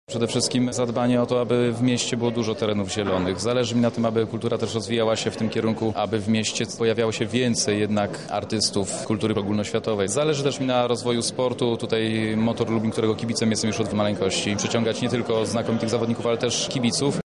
O celach do zrealizowania mówi Marcin Jakóbczyk, radny PiS: